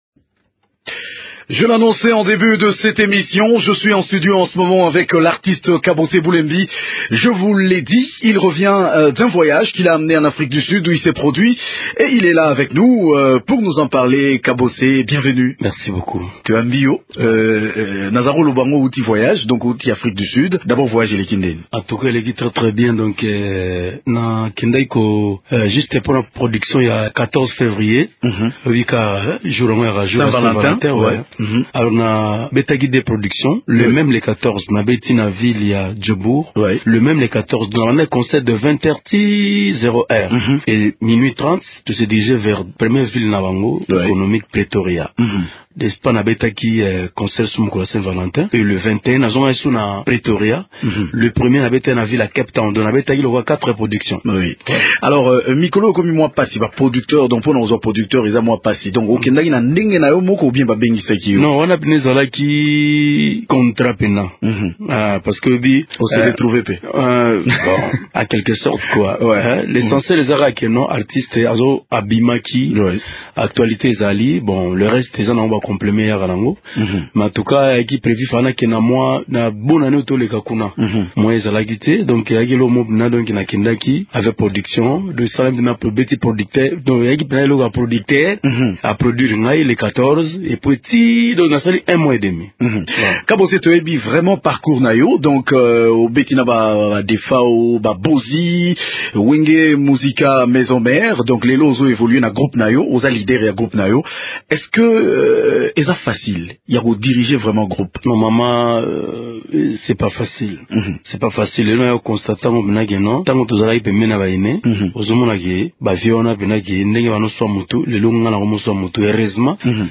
cet entretien